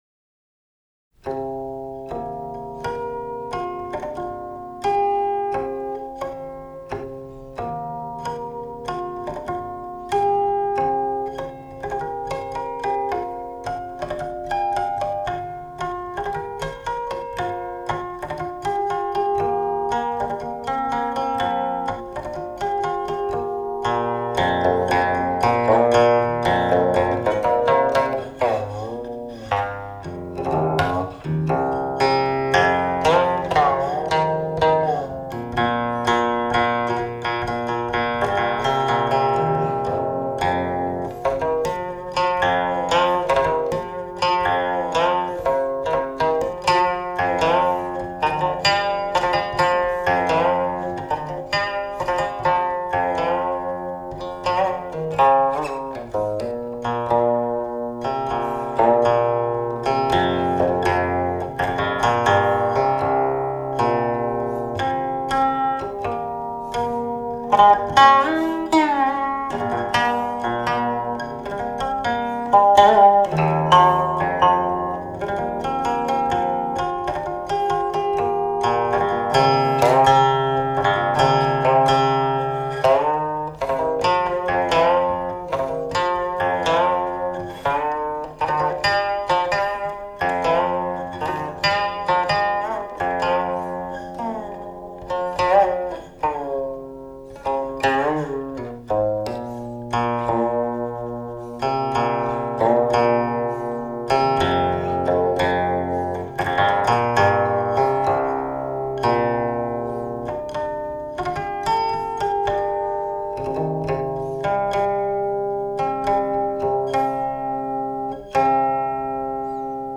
版本: 1997年录音